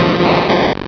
Cri de M. Mime dans Pokémon Rubis et Saphir.